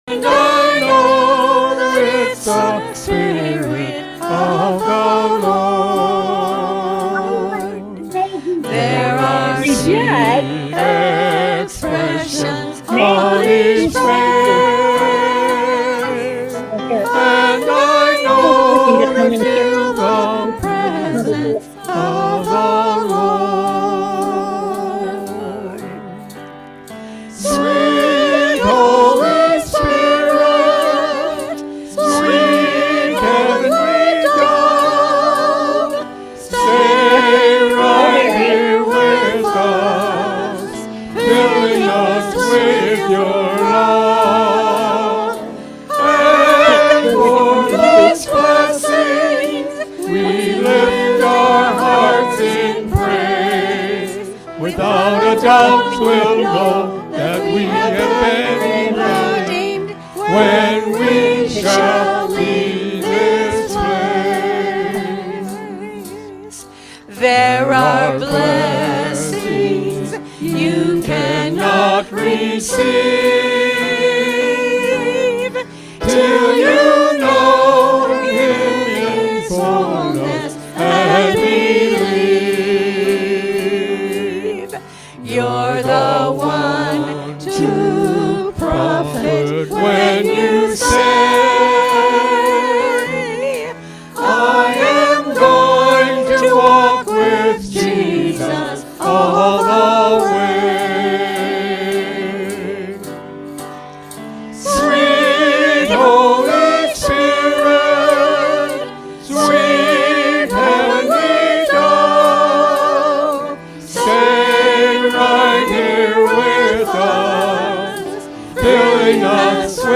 Worship-March-3-2024-Voice-Only.mp3